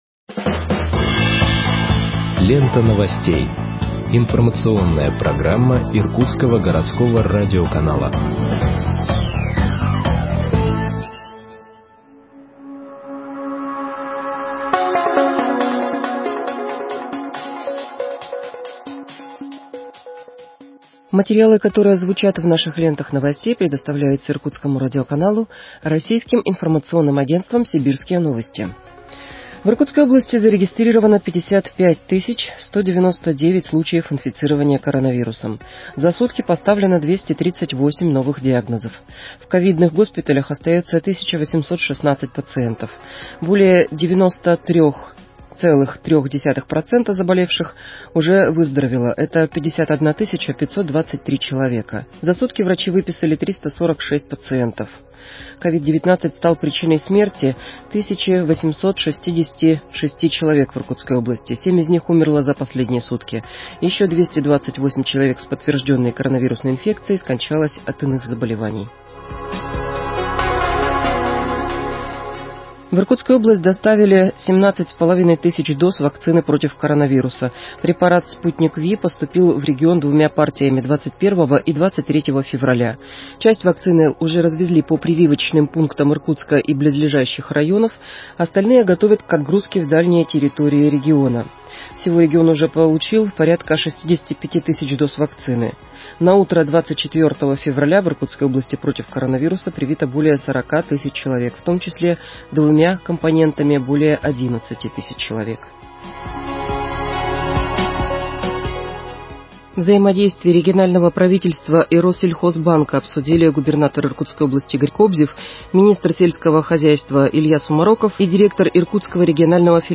Выпуск новостей в подкастах газеты Иркутск от 25.02.2021 № 2